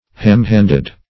ham-fisted \ham-fisted\ ham-handed \ham-handed\adj.